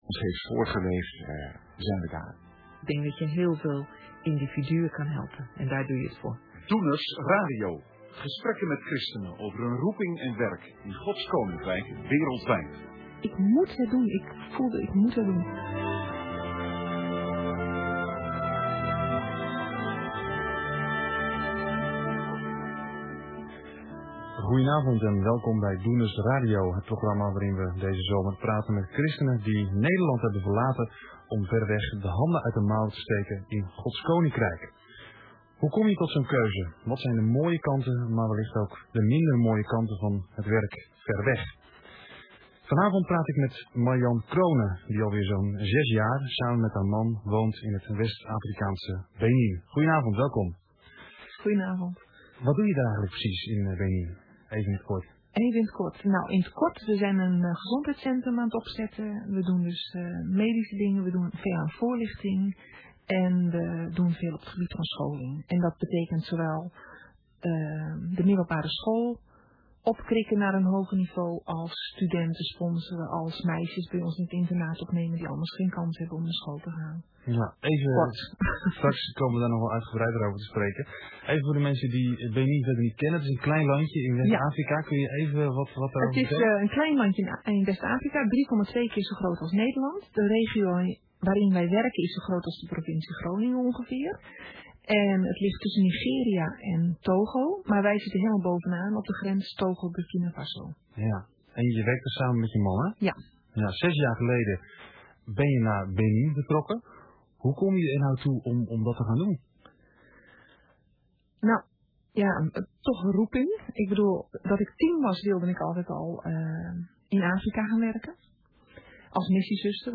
Radio interview
InterviewEO.mp3